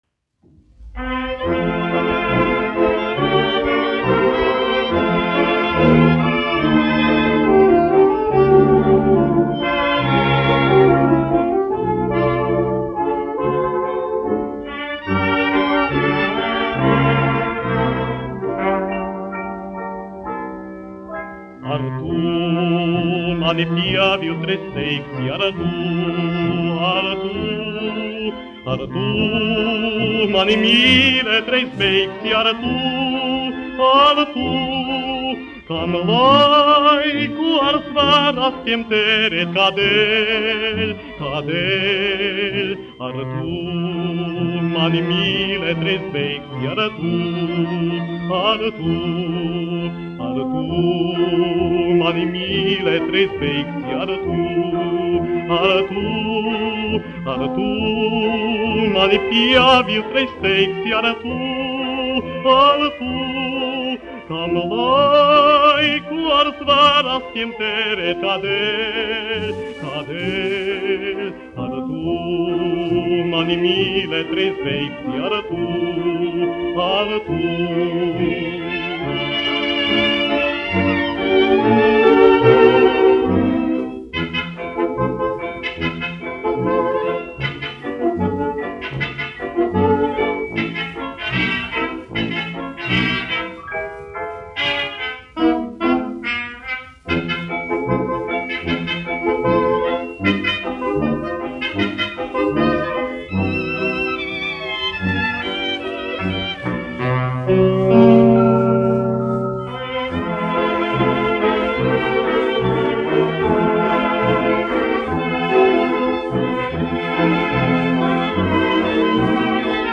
dziedātājs
1 skpl. : analogs, 78 apgr/min, mono ; 25 cm
Fokstroti
Populārā mūzika
Skaņuplate